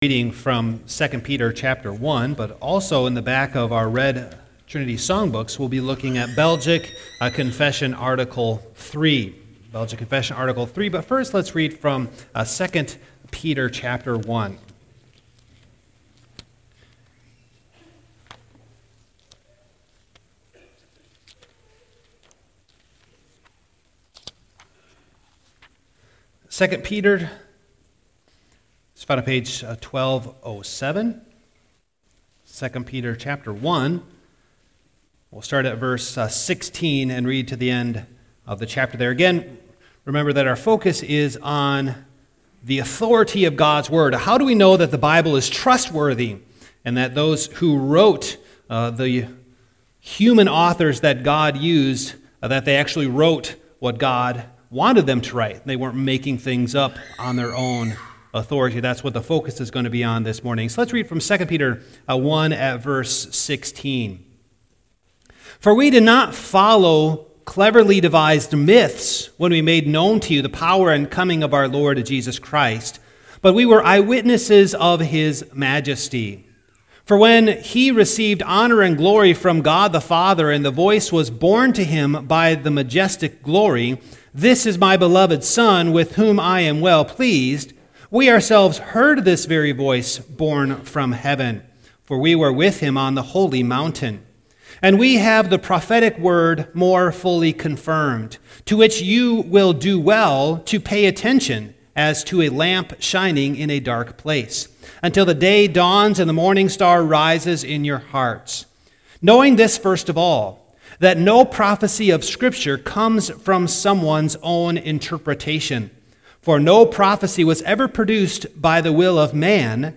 Scripture Text: II Peter 1:16-21 Service Type: Morning Download Files Notes « A Church without Division?